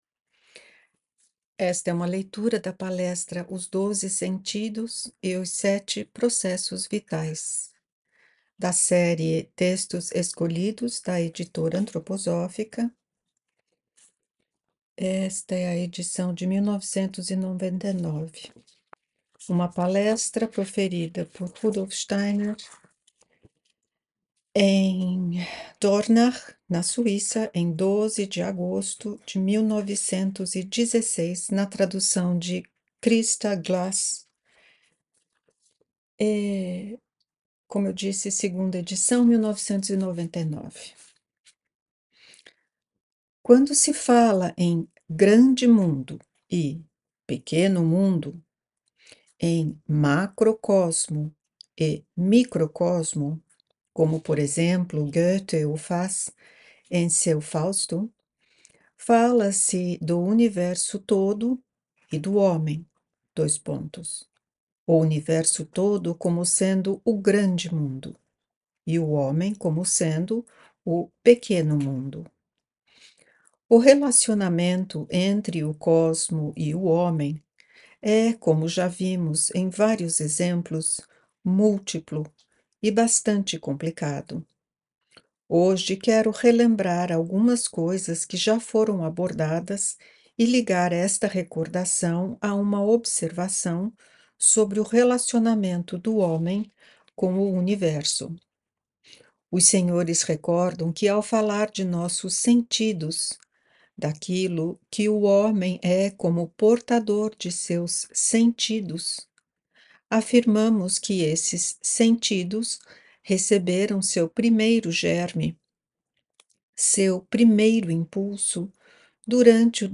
A Rudolf Steiner Audio Brasil oferece, em formato de audiobooks, gravações inteiramente em português a partir da obra direta de Rudolf Steiner.